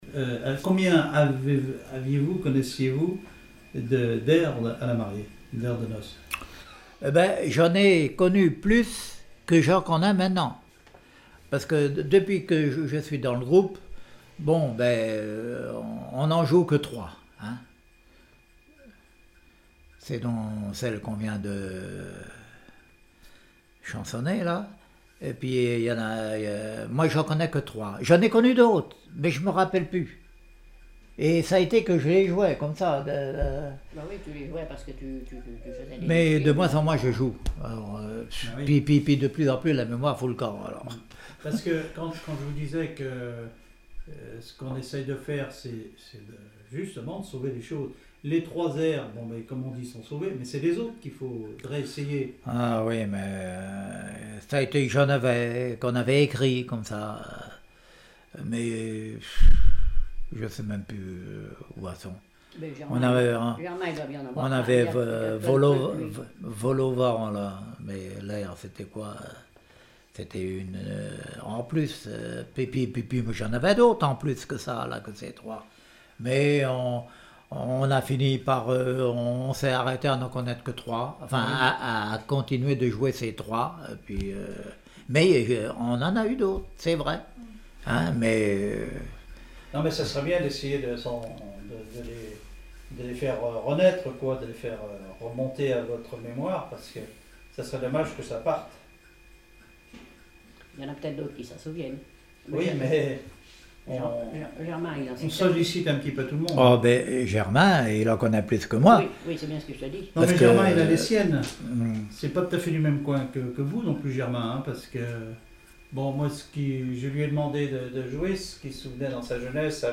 Mémoires et Patrimoines vivants - RaddO est une base de données d'archives iconographiques et sonores.
Témoignage comme joueur de clarinette
Catégorie Témoignage